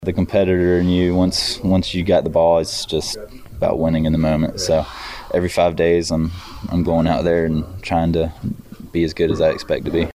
Cards Starting Pitcher Jordan Montgomery after picking up the win against his former team.
Cards-Starting-Pitcher-Jordan-Montgomery-after-picking-up-the-win-against-his-former-team-oc-to-be.mp3